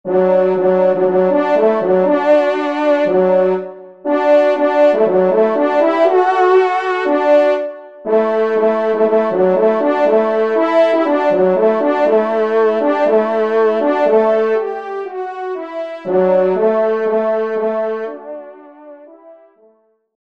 Genre : Fantaisie Liturgique pour quatre trompes
Pupitre 2°Trompe